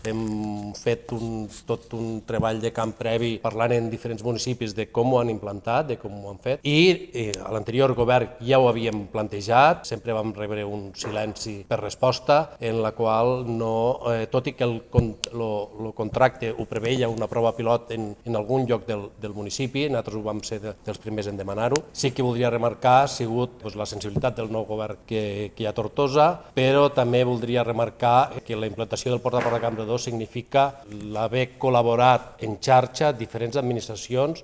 Per la seua banda, l’alcalde de Campredó, Damià Grau, ha explicat que esta era una antiga demanda de l’EMD perquè la situació de les actuals illes de contenidors provoca problemes de males olors i mosques als veïns.